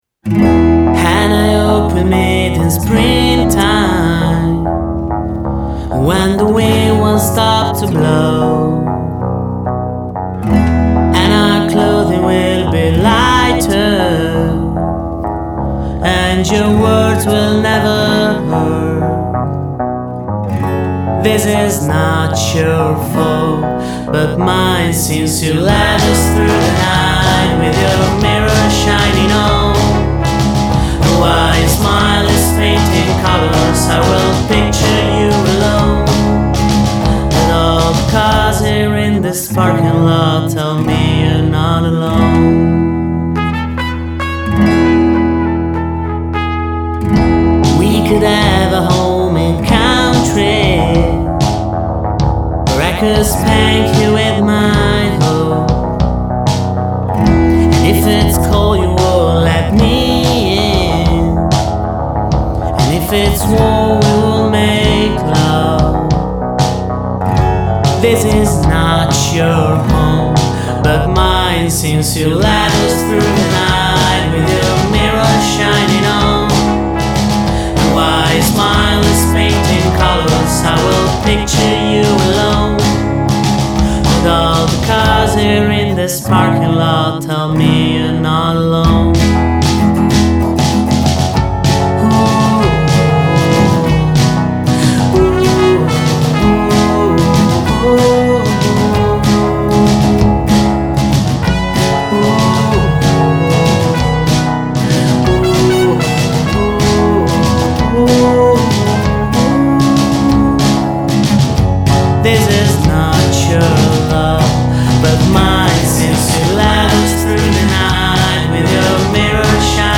Luogo esecuzioneStop! Studio
GenereWorld Music / Folk